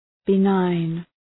Shkrimi fonetik {bı’naın}